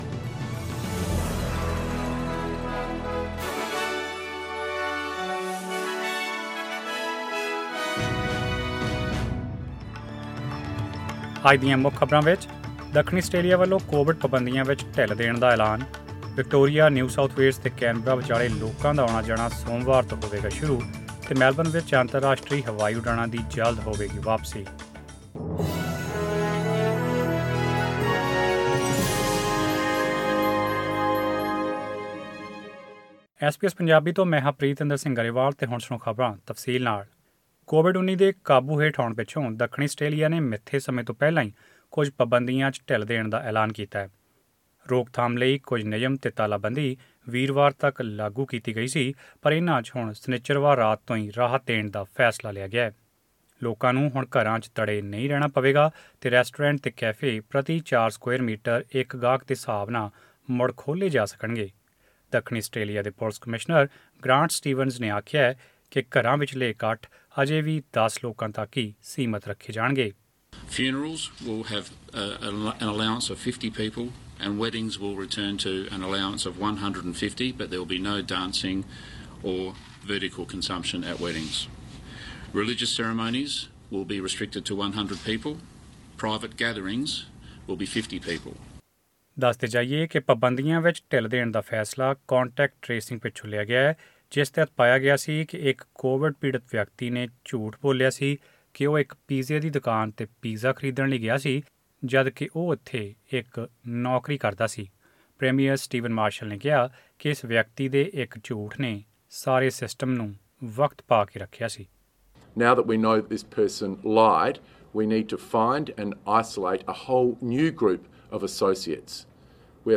Australian News in Punjabi: 20 November 2020